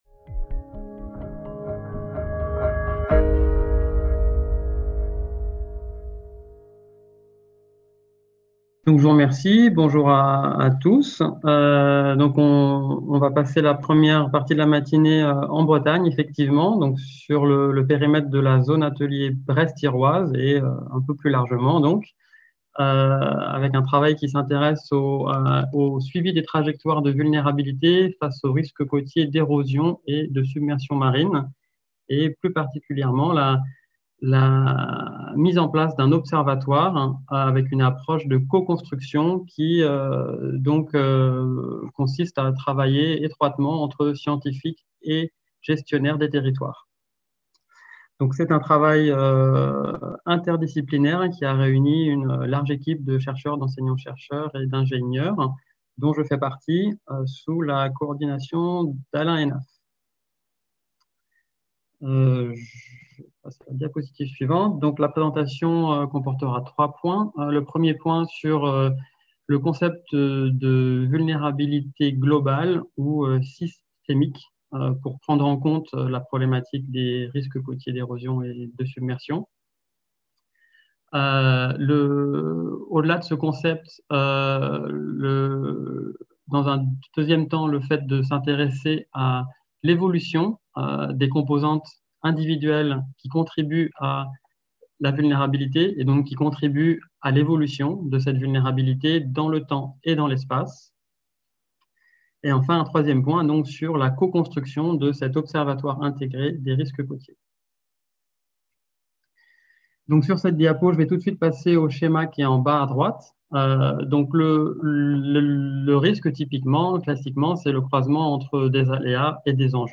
Conférence plénière - OSIRISC : co-construction d'un observatoire pour le suivi des trajectoires de vulnérabilité face aux risques côtiers en Bretagne(ZA Brest-Iroise) | Canal U